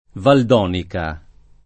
vai all'elenco alfabetico delle voci ingrandisci il carattere 100% rimpicciolisci il carattere stampa invia tramite posta elettronica codividi su Facebook Valdonica [ vald 0 nika ] top. — luogo nell’Oltrepò pavese; strada in Bologna